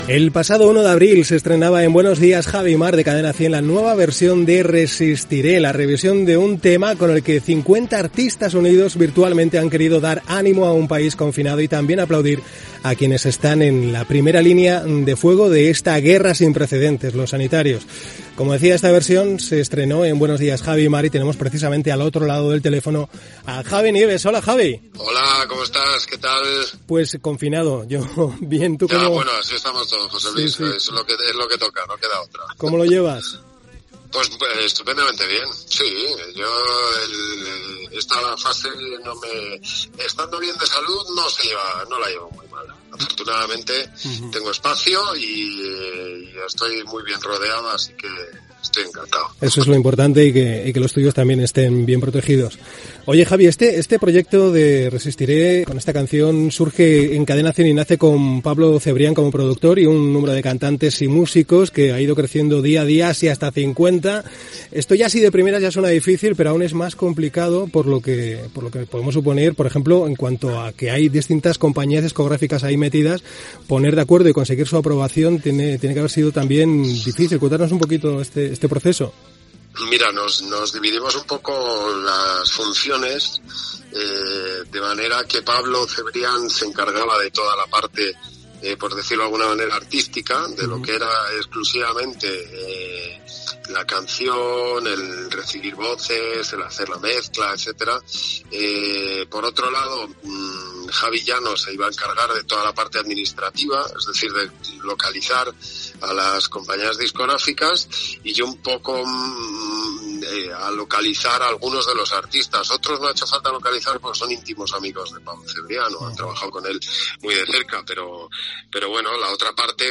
Fragment d'una entrevista telemàtica al presentador Javi Nieves de "Buenos días Javi y Mar" que van tenir la iniciativa de fer versions de la cançó "Resistiré" amb 50 artistes diferents durant la pandèmia del coronavirus (COVID-19).
Musical